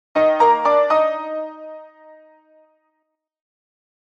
piano, into